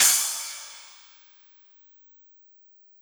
Cymbol Shard 06.wav